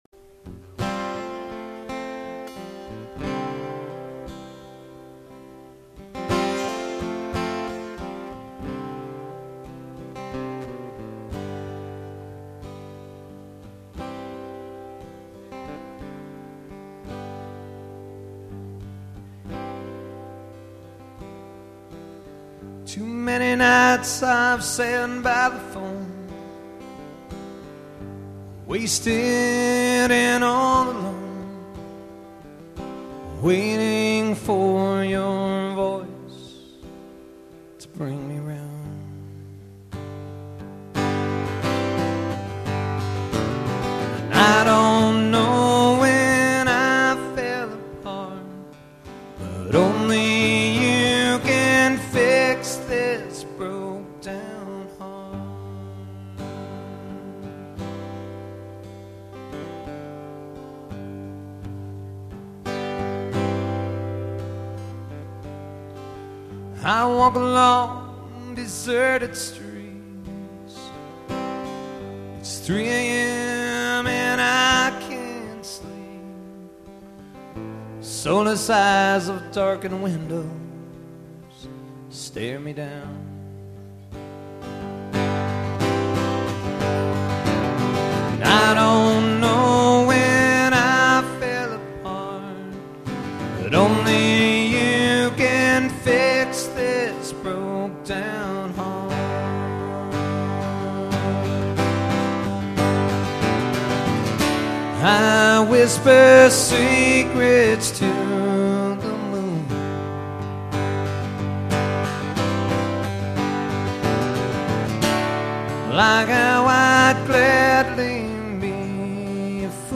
solo acoustic performances